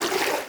swim3.wav